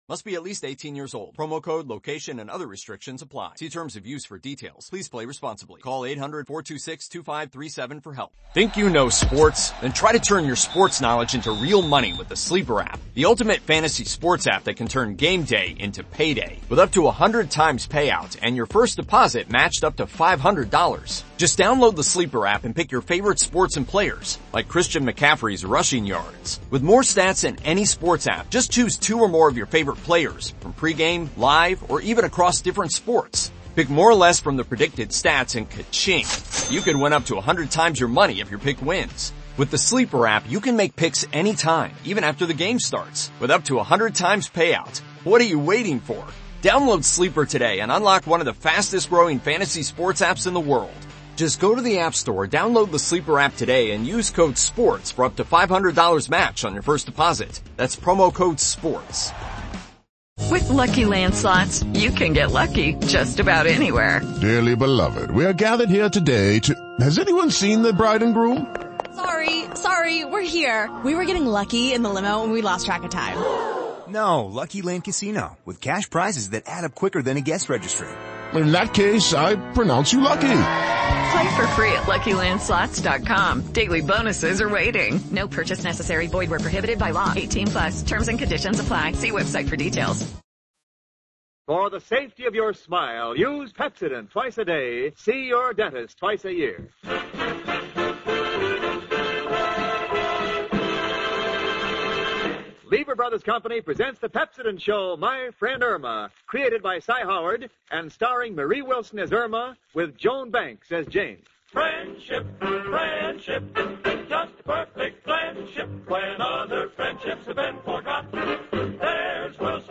"My Friend Irma," the classic radio sitcom that had audiences cackling from 1946 to 1952!
Irma, played to perfection by the inimitable Marie Wilson, was the quintessential "dumb blonde."